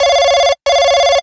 phonering.wav